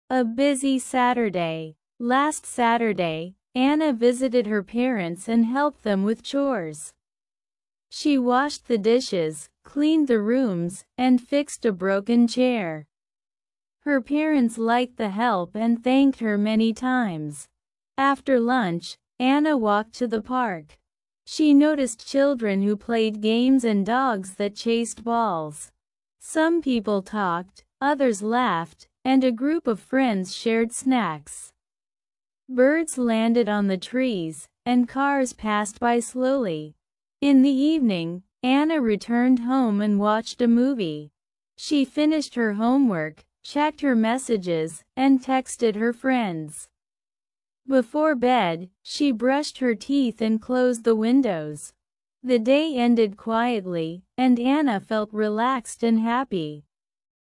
A Busy Saturday (-ed & Plural -s Pronunciation Practice)